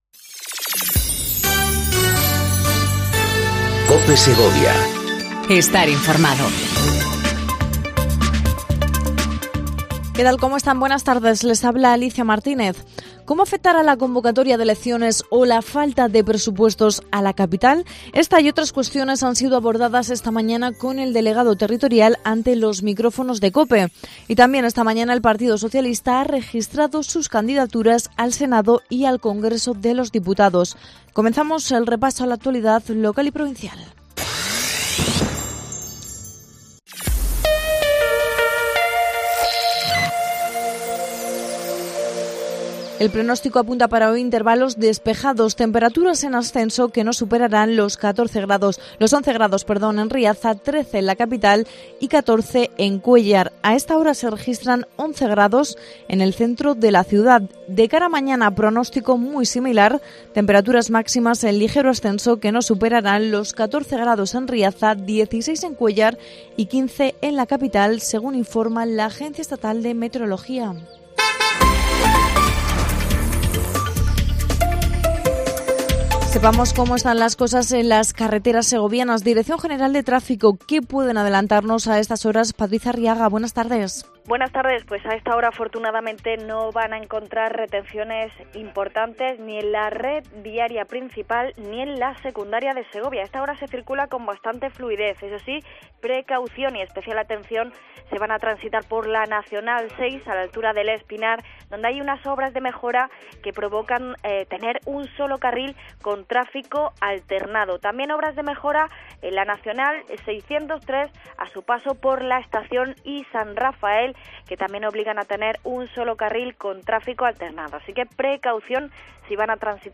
INFORMATIVO DEL MEDIODÍA EN COPE SEGOVIA 14:20 DEL 20/03/19